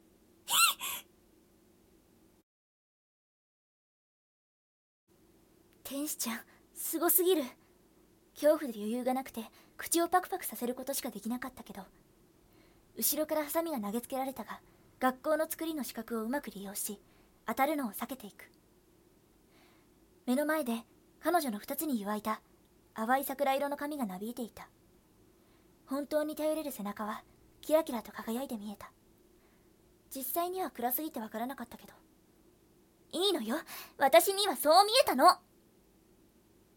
【💭🎀】台本37 ナレ+台詞 ③ nanaRepeat